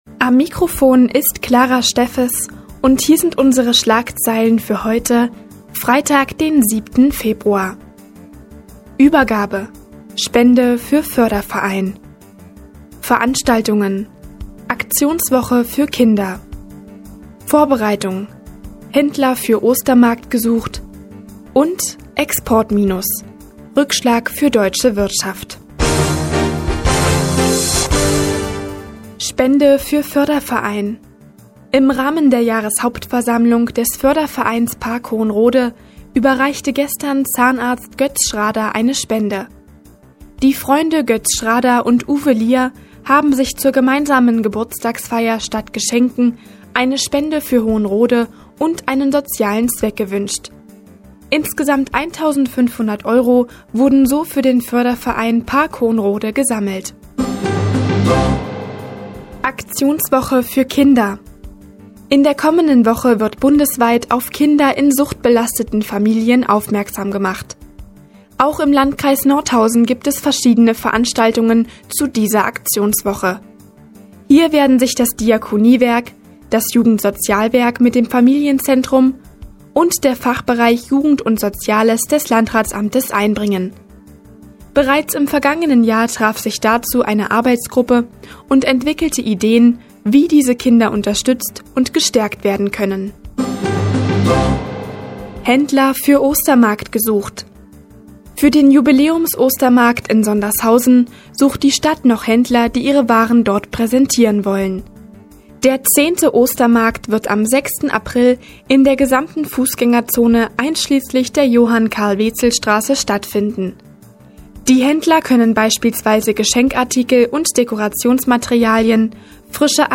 Die tägliche Nachrichtensendung des OKN ist jetzt hier zu hören.